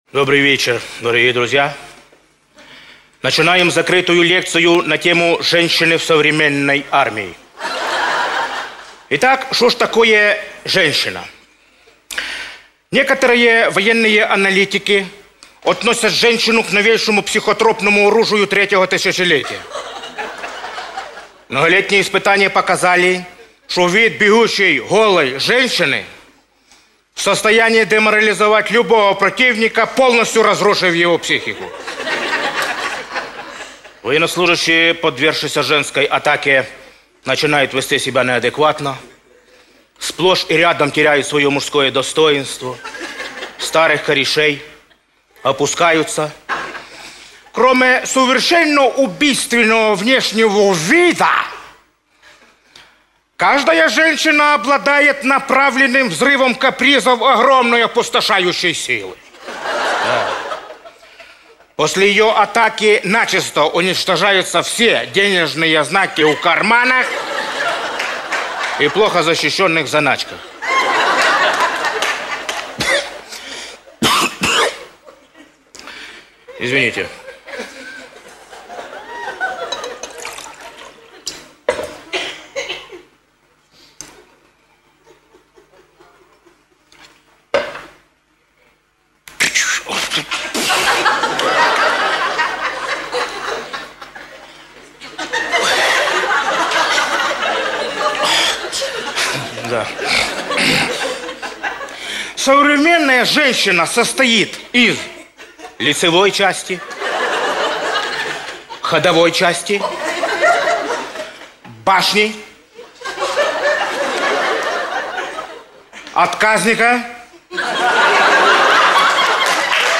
Лекция про женщин Игоря Маменко
Lekciya-pro-zhenschin-Igorya-Mamenko.mp3